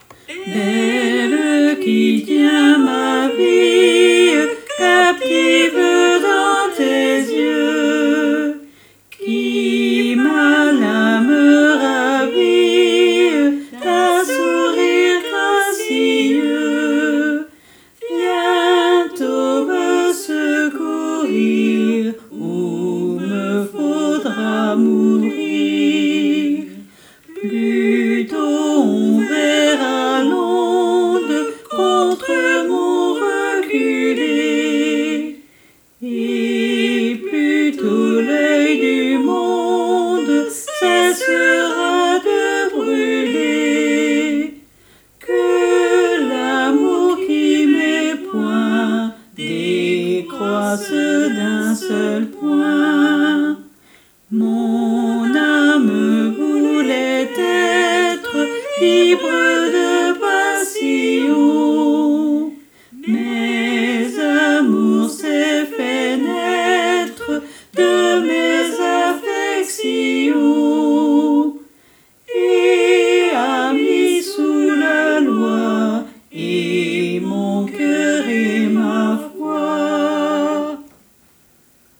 Alto et autres voix en arrière-plan